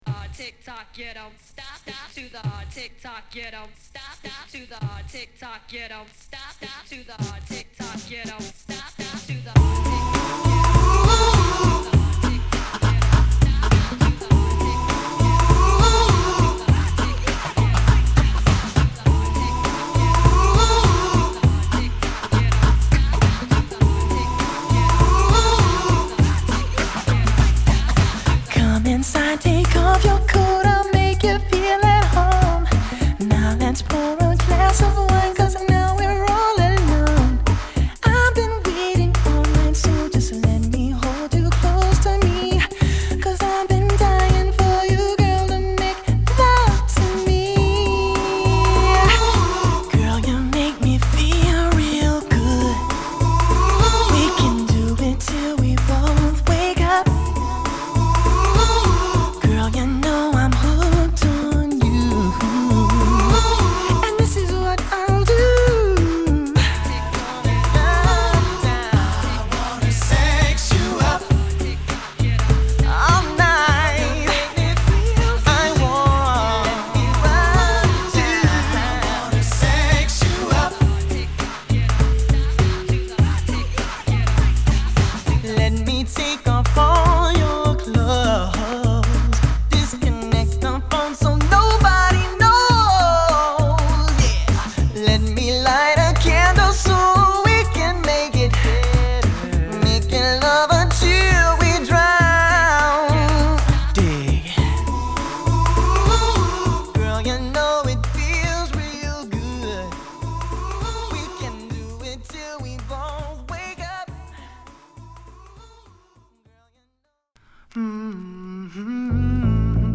オクラホマのヴォーカル・グループ
弾んだ小気味良いリズムに抜群のコーラスとセクシャルなリリックが乗るクラシック！